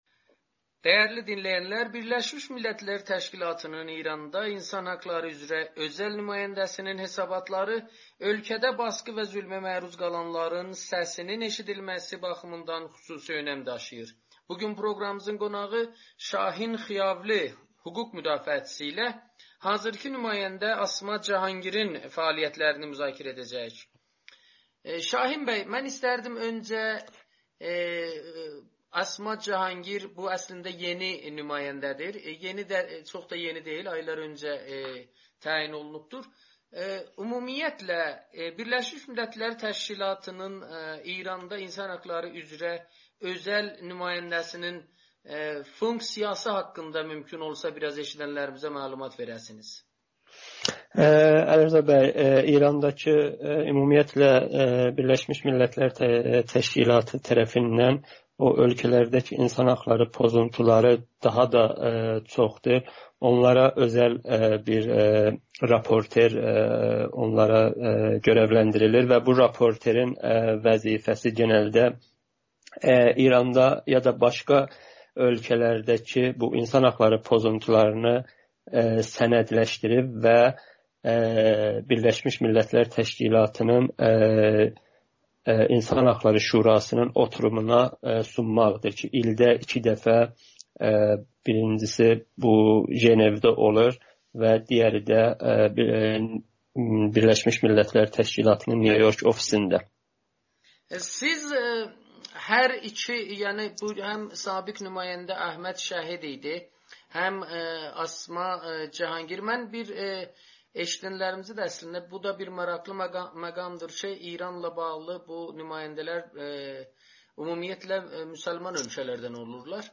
Bir problemimiz BMT-də bir təmsiliçimizin olmamasıdır [Audio-Müsahibə]